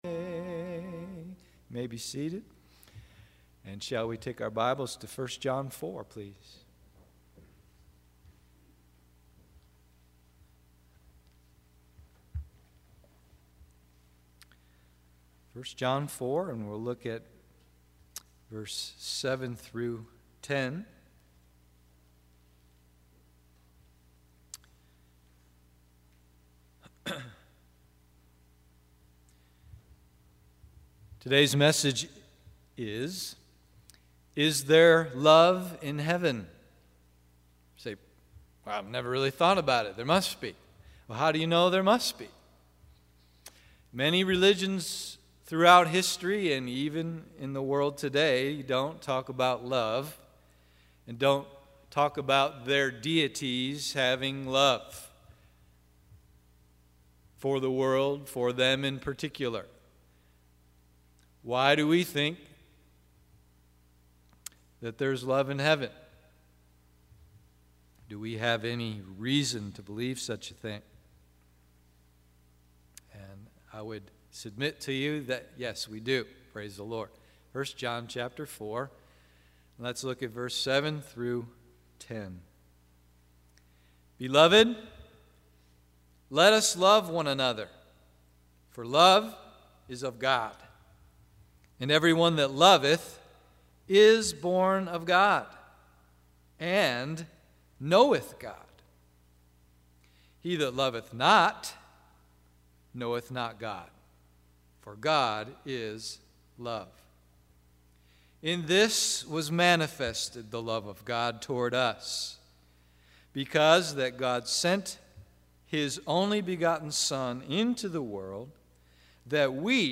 Is there Love in Heaven? AM Service